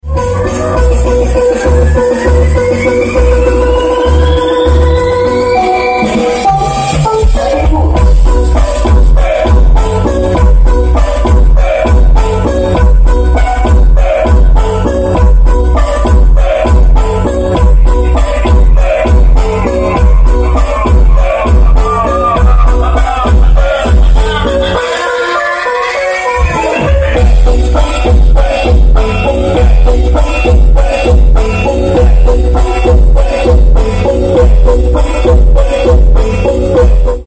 pakai karnavalan sekitar tahun 2018 an